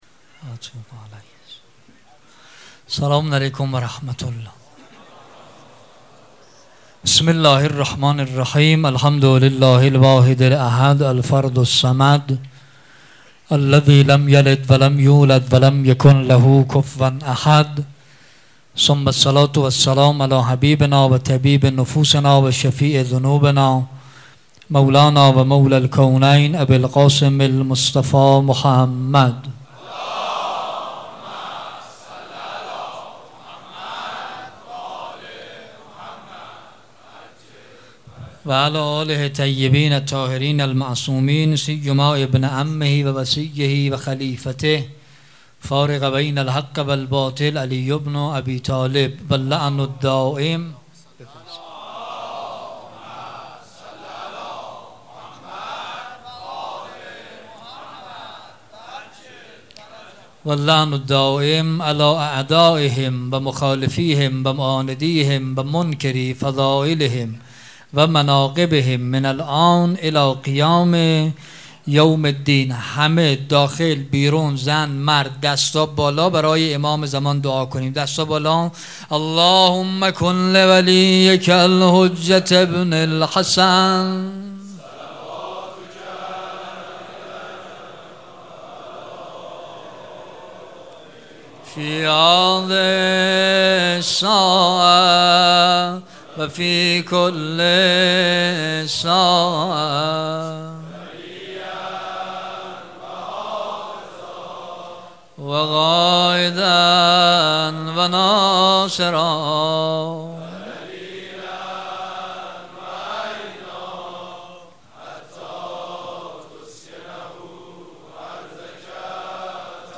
سخنرانی
شب تاسوعا
مراسم عزاداری شب تاسوعا